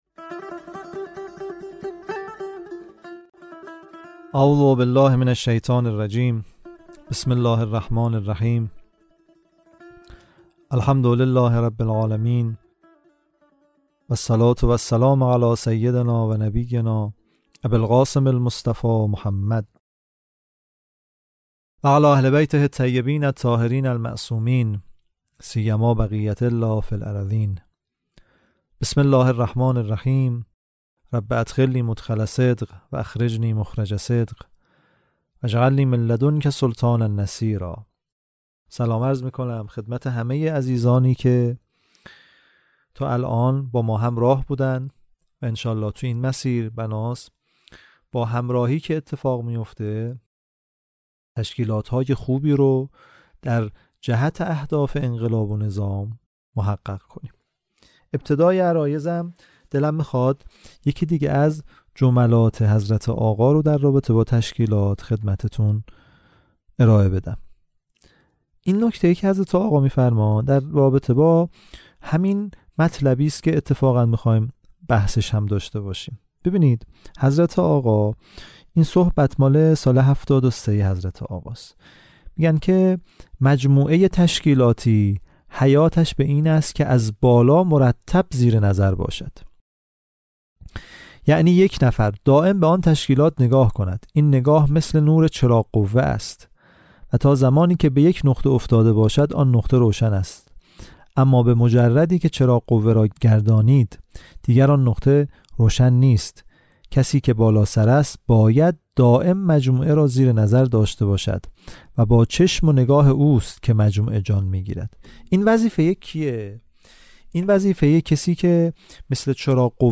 دوازدهمین جلسه «دوره آموزشی تشکیلات» | مدرس دوره